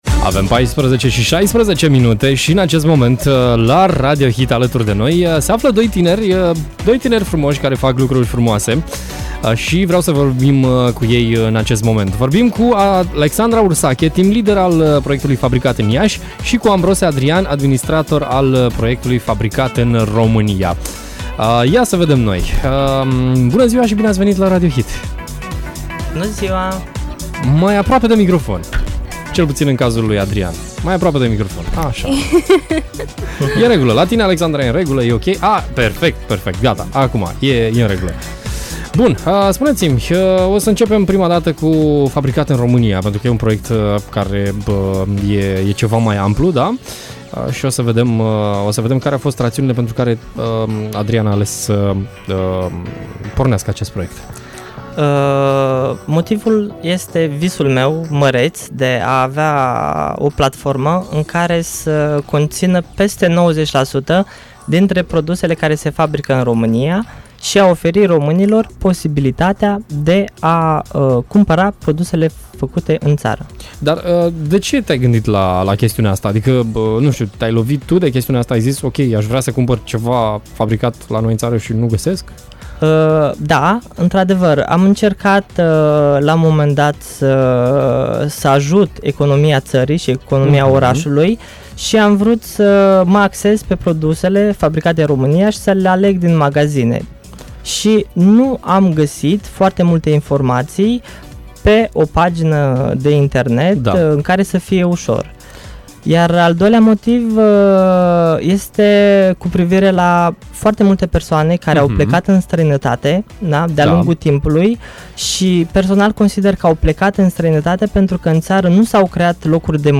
Mai multe detalii am aflat în direct la Radio Hit